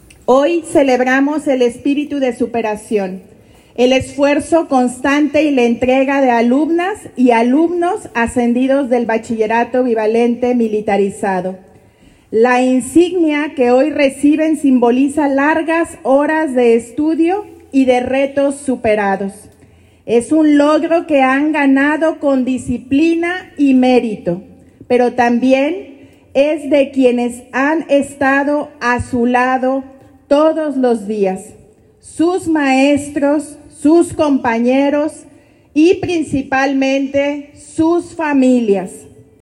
AudioBoletines
Irapuato, Gto. 05 de septiembre del 2025 .- En una ceremonia marcada por la solemnidad, 272 cadetes del Bachillerato Bivalente Militarizado de Irapuato recibieron ascensos y reconocimientos por su desempeño académico, disciplina y liderazgo.
Lorena Alfaro García, presidenta municipal